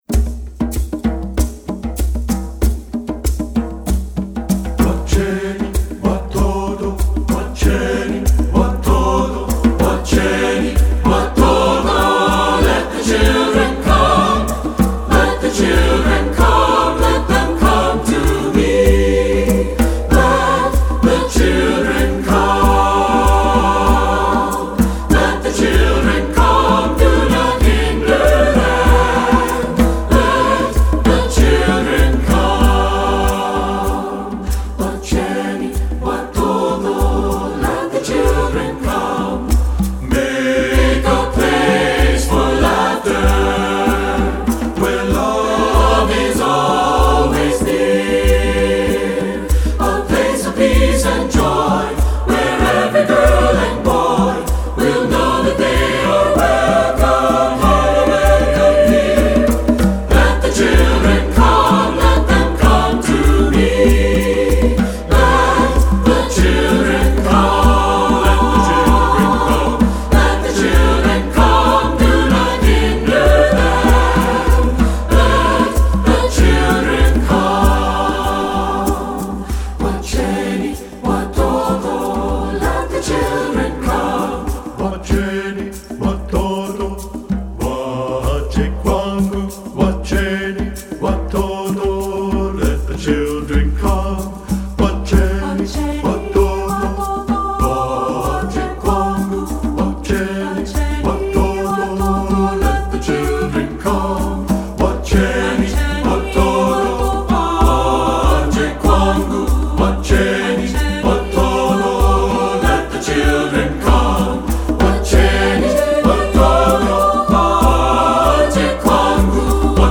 Voicing: SATB and Percussion